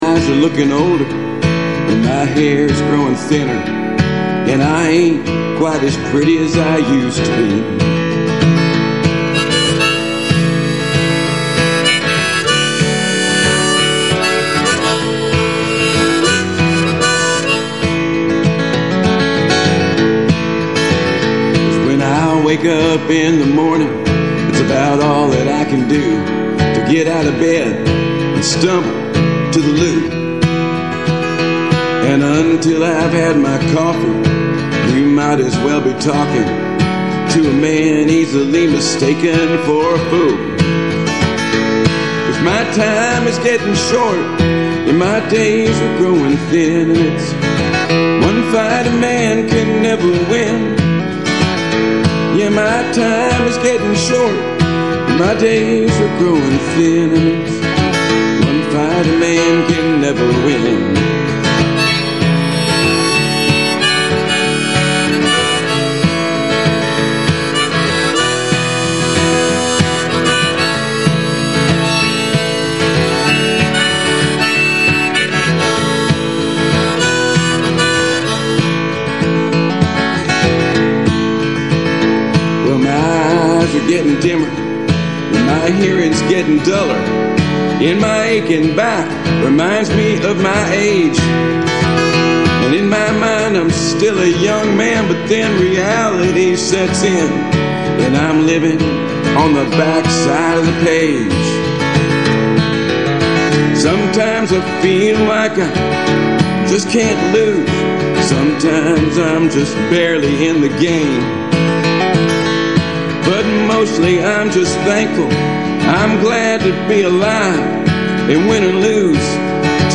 radio show
musical guest